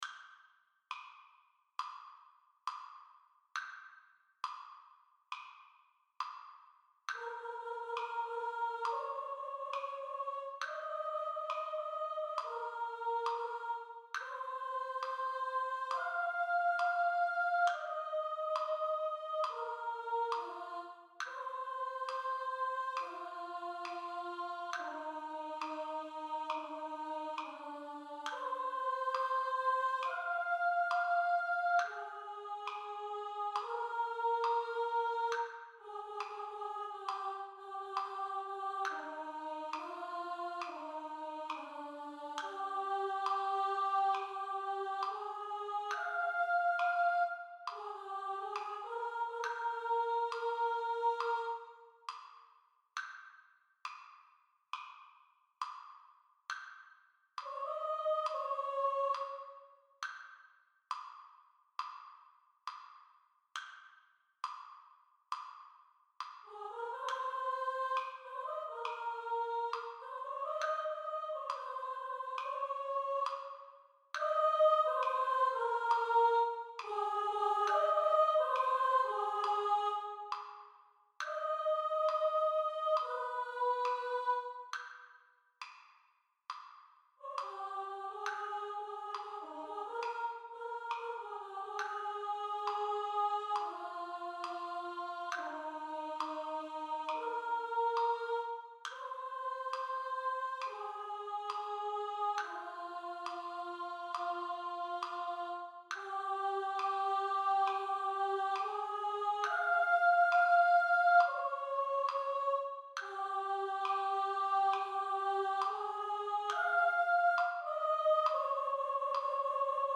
Let-It-Go-Soprano-1 | Ipswich Hospital Community Choir
Let-It-Go-Soprano-1.mp3